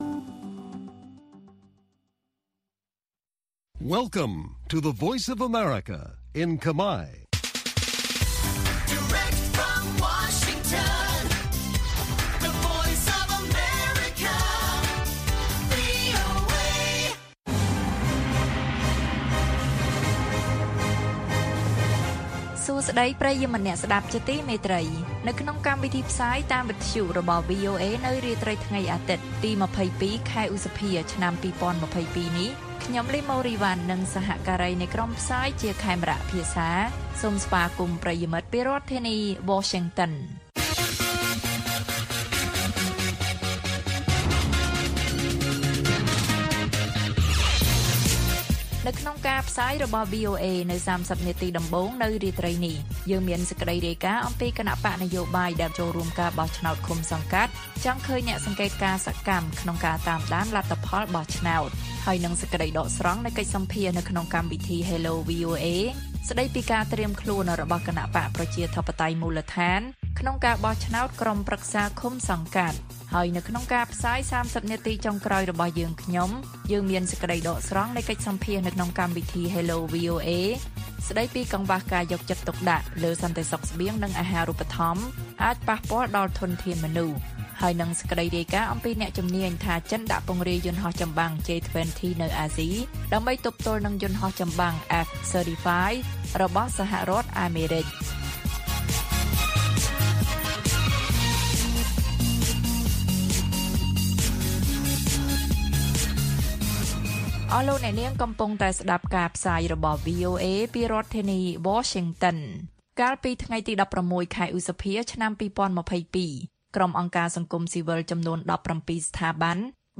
ព័ត៌មាននៅថ្ងៃនេះមាន គណបក្សនយោបាយដែលចូលរួមការបោះឆ្នោតឃុំសង្កាត់ ចង់ឃើញអ្នកសង្កេតការណ៍សកម្មក្នុងការតាមដានលទ្ធផលបោះឆ្នោត។ សេចក្តីដកស្រង់នៃកិច្ចសម្ភាសន៍ក្នុងកម្មវិធី Hello VOA ស្តីពី«ការត្រៀមខ្លួនរបស់គណបក្សប្រជាធិបតេយ្យមូលដ្ឋានក្នុងការបោះឆ្នោតក្រុមប្រឹក្សាឃុំសង្កាត់» និងព័ត៌មានផ្សេងទៀត៕